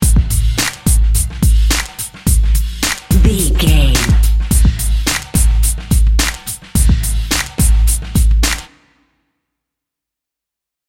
Aeolian/Minor
drum machine
synthesiser
hip hop
Funk
neo soul
acid jazz
energetic
bouncy
Triumphant
funky